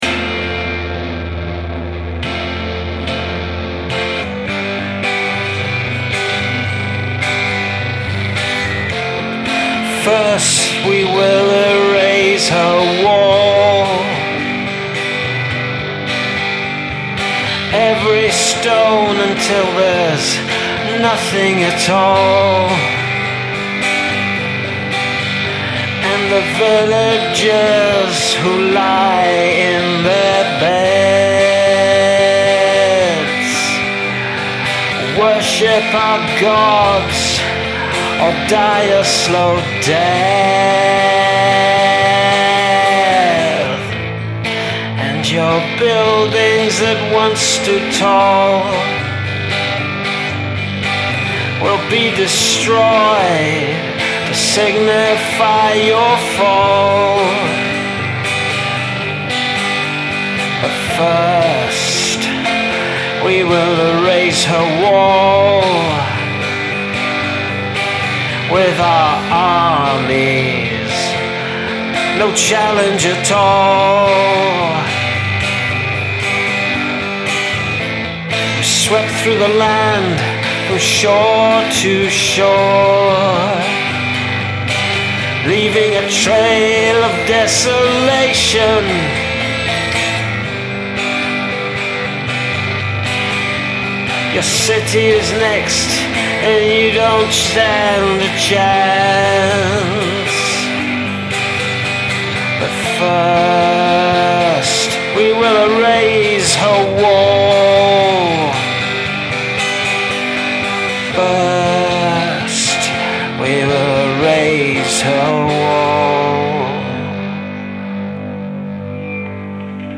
Dark and intense images.